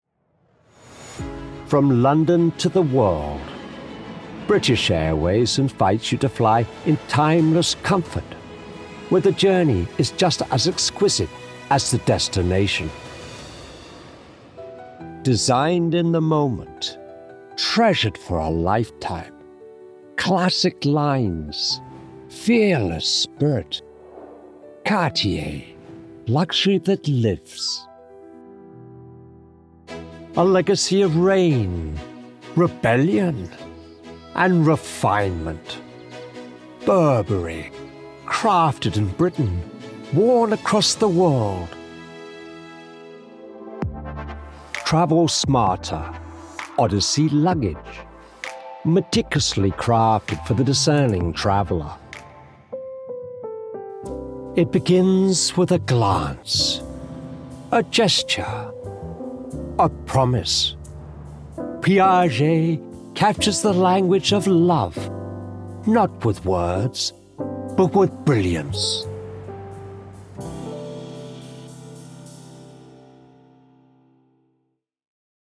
1110VoiceRealm_LuxeryCommercial.mp3